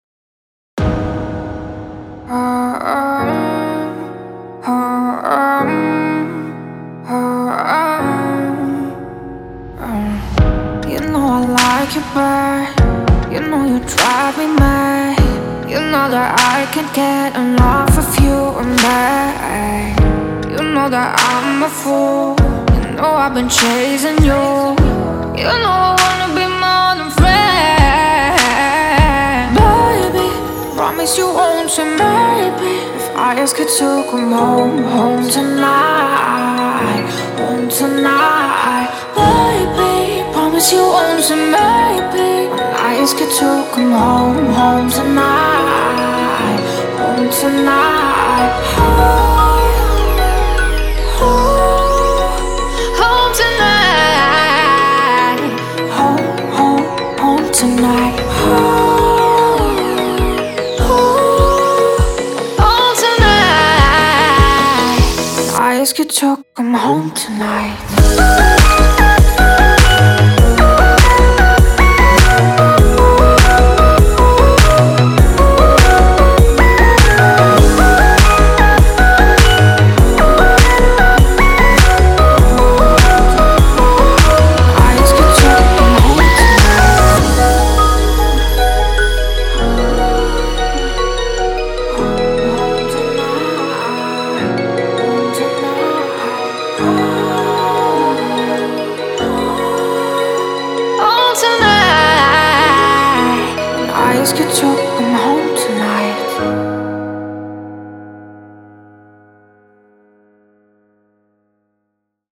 它包含来自7位专业歌手22种全无伴奏合唱，适用于各种音乐风格。